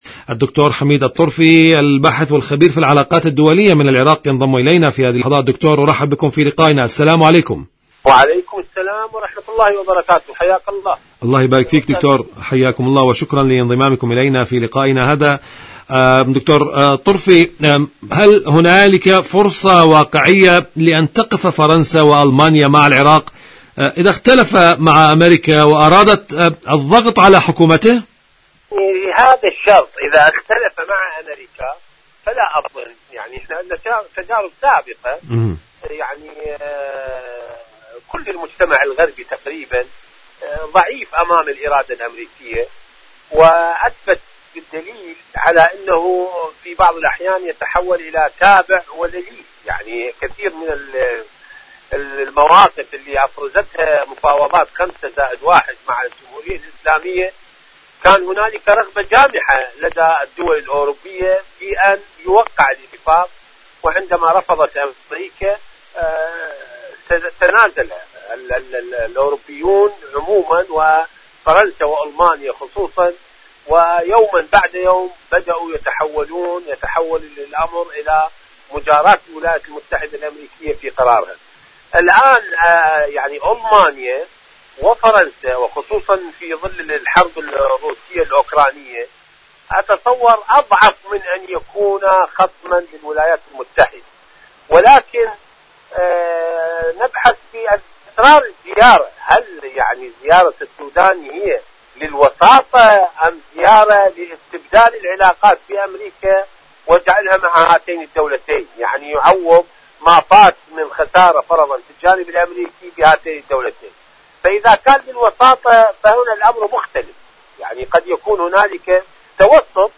مقابلات برامج إذاعة طهران العربية برنامج عراق الرافدين العراق أمريكا فرنسا ألمانيا الضغوط الأمريكية مقابلات إذاعية شاركوا هذا الخبر مع أصدقائكم ذات صلة الردع الإيراني والمقاومة الفلسطينية..